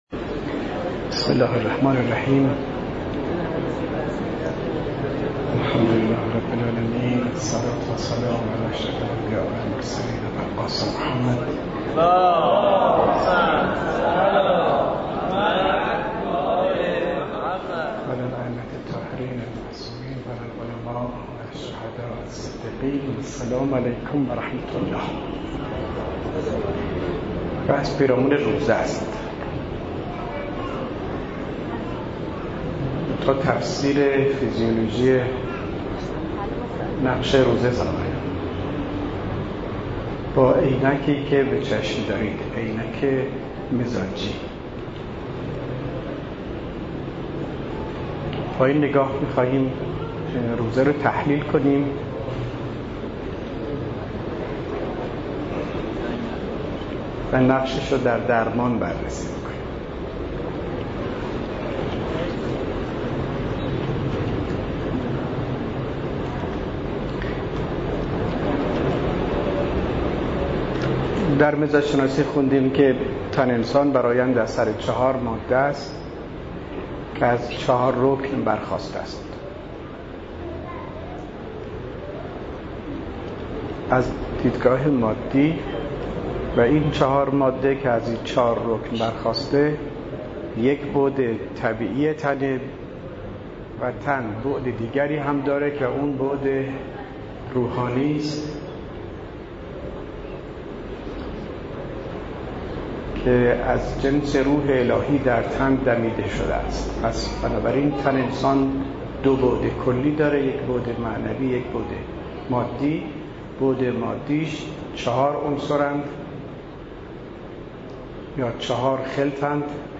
صوت جلسات طب سنتی اسلامی قم ، 13 / 3 / 95 (1)
بحث تحلیل روزه از نگاه مزاج شناسی و نقش آن در درمان ، قم حسینیه موسی بن جعفر